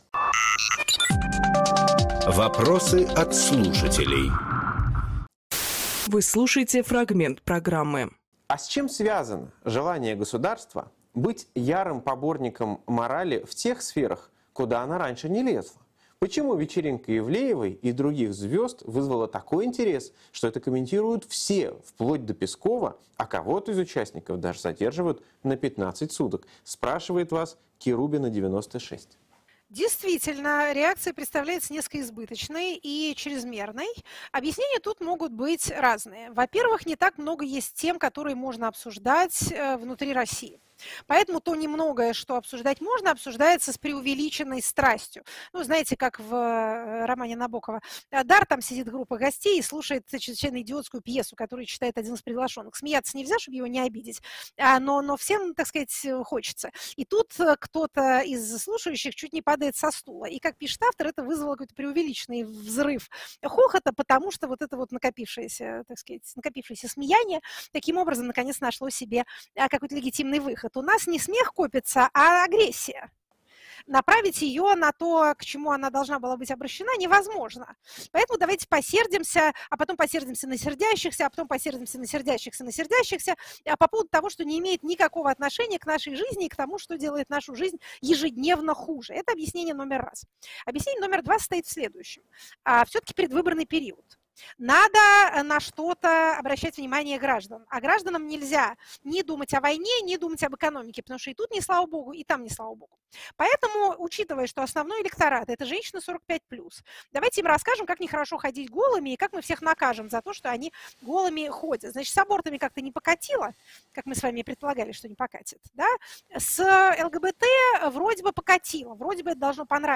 Фрагмент эфира от 26.12